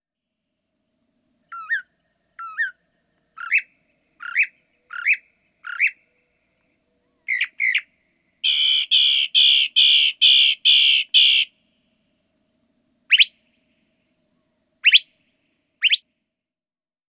Northern Mockingbird
A male may learn up to 200 different songs. How they sound: These birds tend to whistle a series different phrases, mimicking the sounds of birds (and frogs!) around them.
Northern_Mockingbird_1_Song.mp3